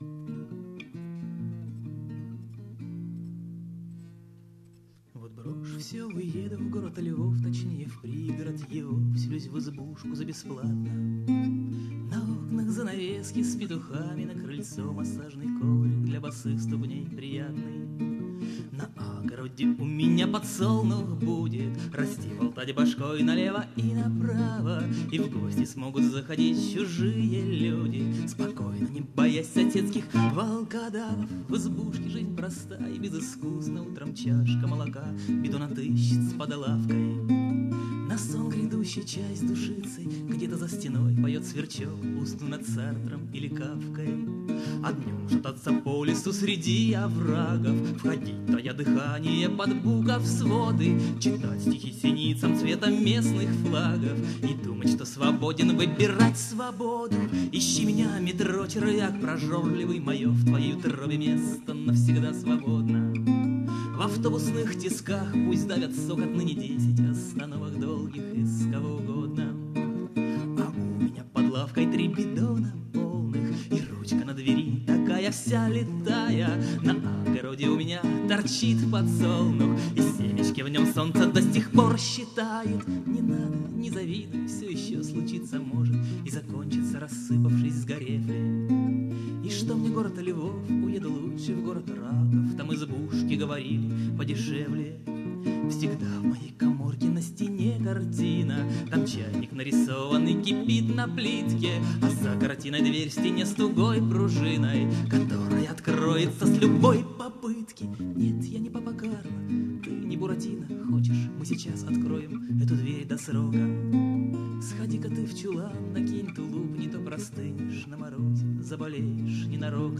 Концерт 20.05.2006 года.
Открытие бард-гостиной